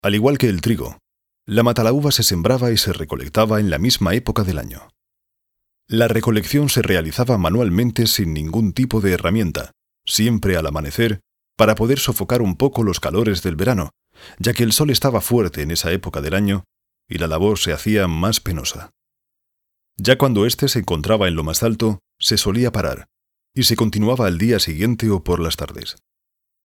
kastilisch
Sprechprobe: Werbung (Muttersprache):
I have my own recording studio, with RODE mics and Apple Computers.